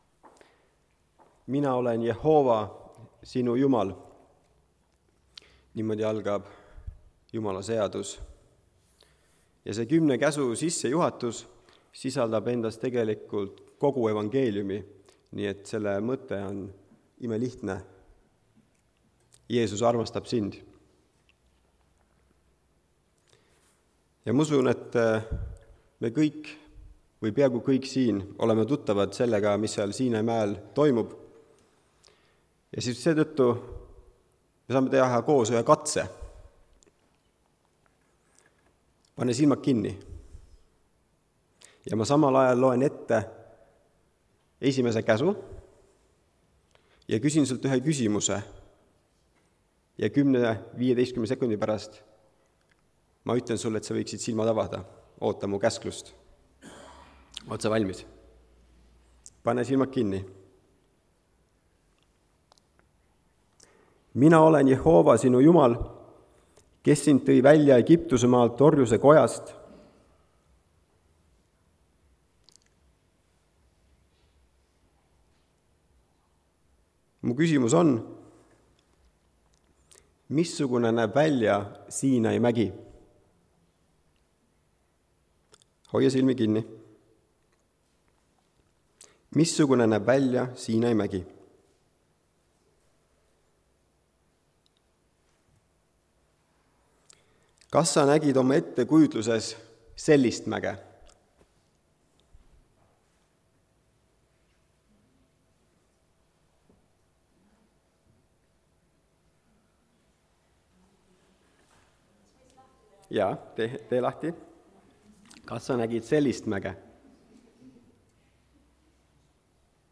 Jehoova poeg Jehoova (Tallinnas)
Jutlused